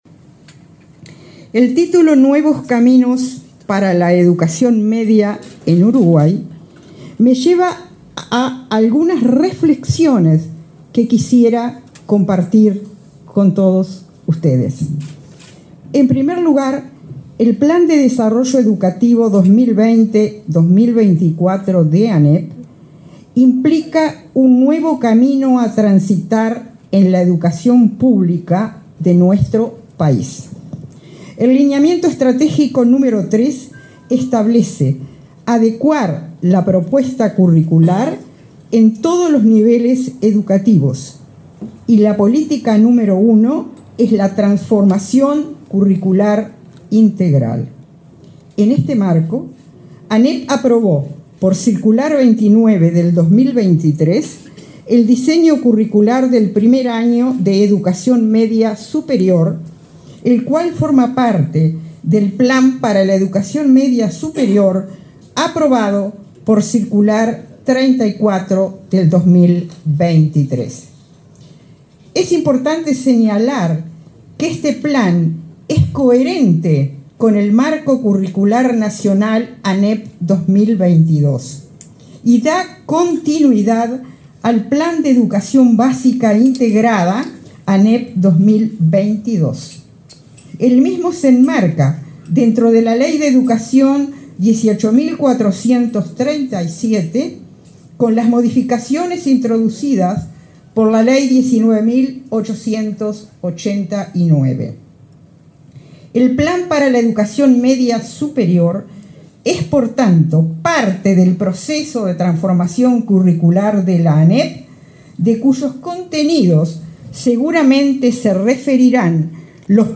Palabra de autoridades en acto de la ANEP